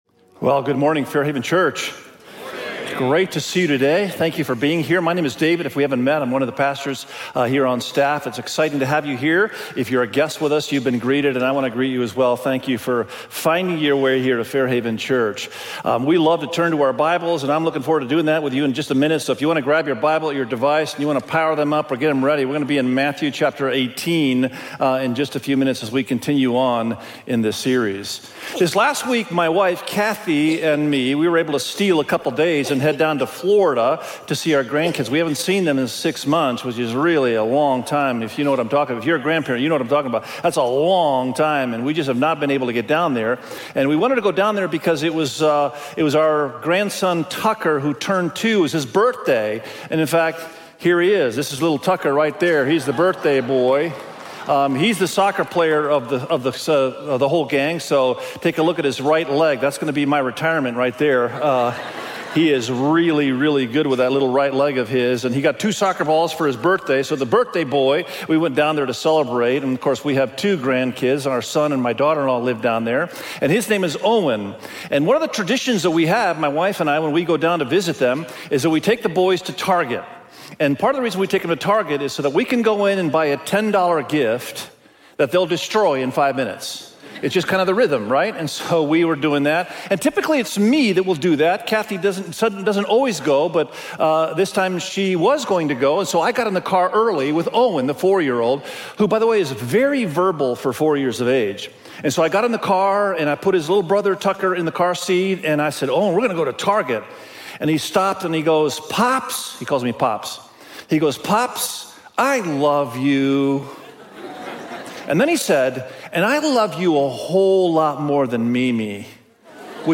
Unreasonable-Forgiveness_SERMON.mp3